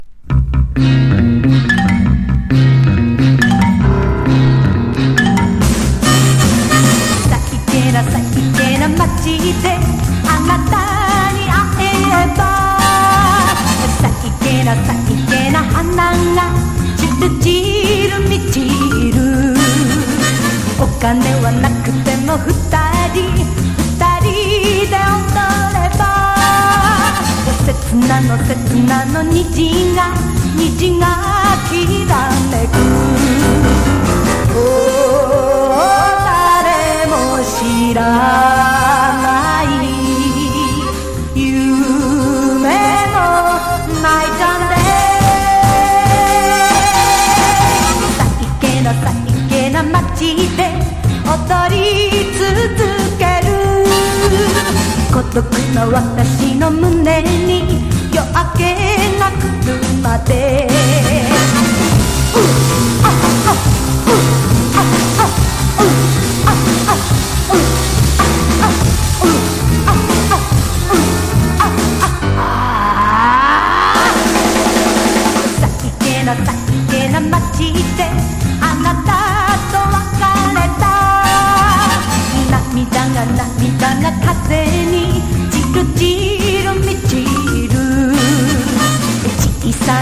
昭和歌謡からカルトGSまでクラブヒット級のみ収録した持ってて損はない1枚!：JAPANESE